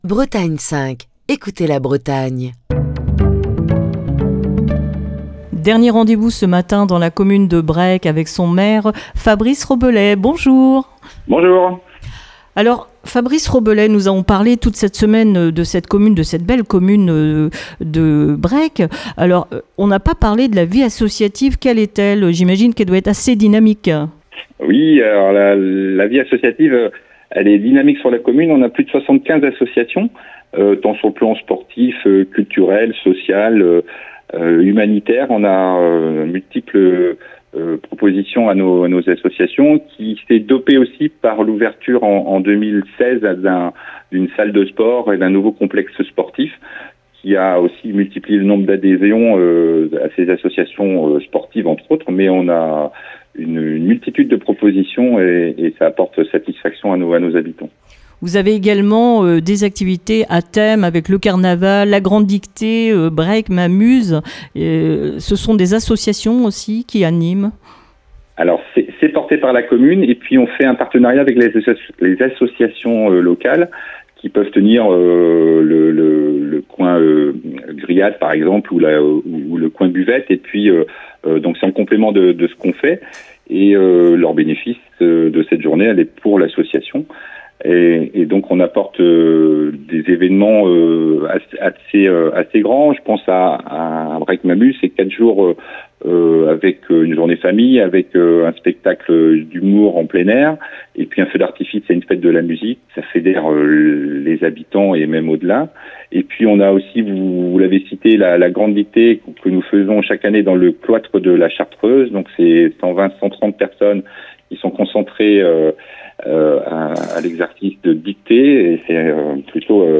maire de Brec'h dans le Morbihan, où Destination Commune avait posé ses micros.